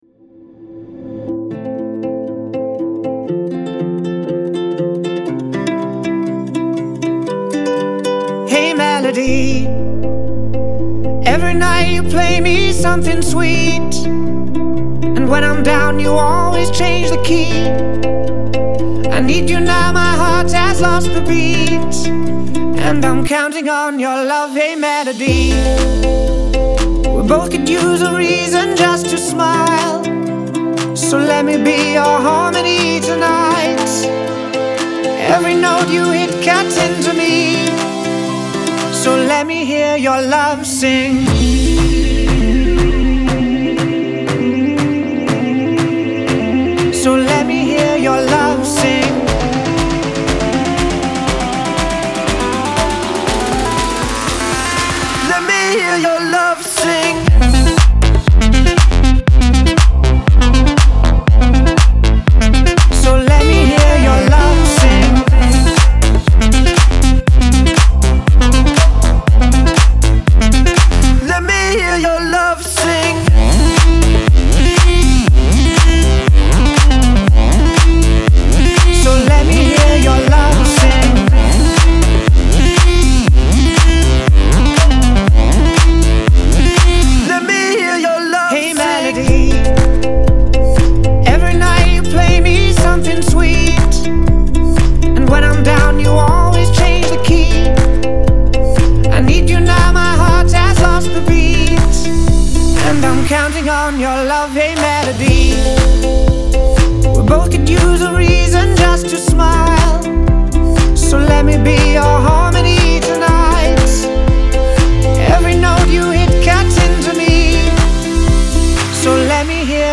Genre : House